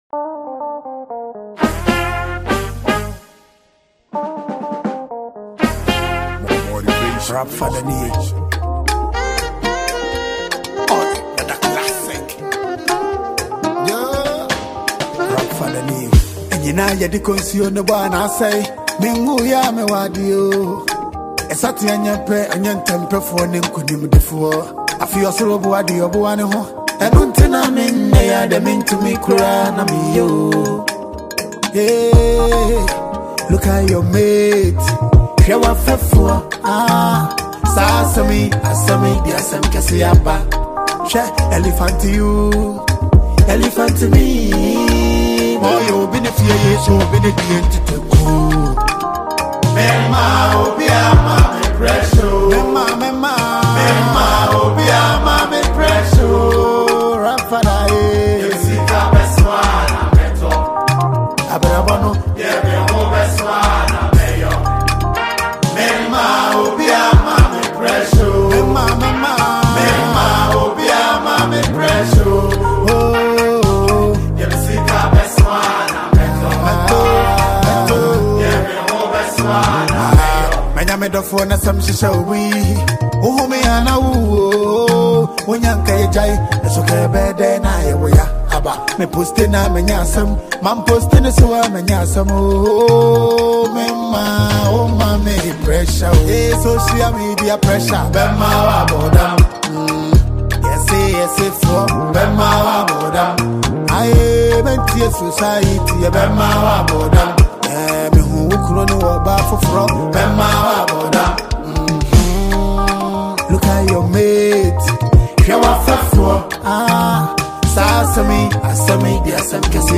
Ghanaian rapper and songwriter